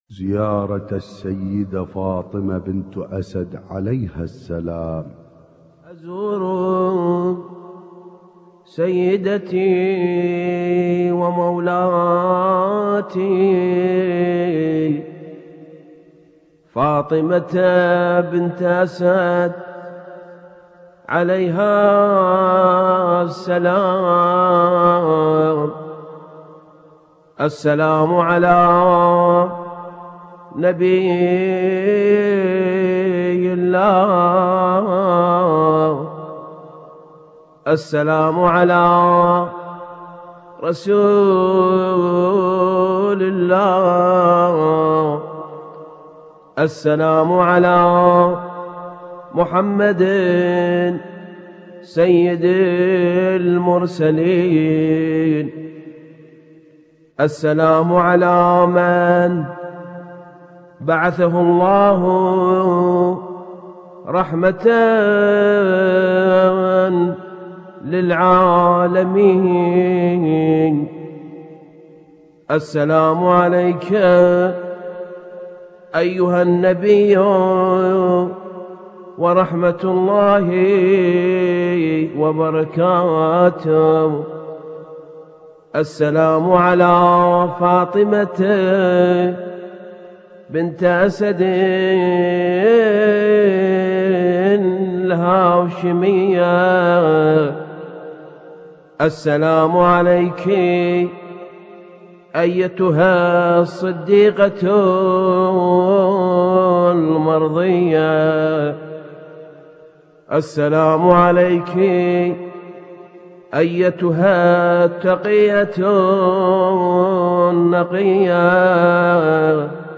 اسم التصنيف: المـكتبة الصــوتيه >> الزيارات >> سلسلة النور